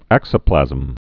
(ăksə-plăzəm)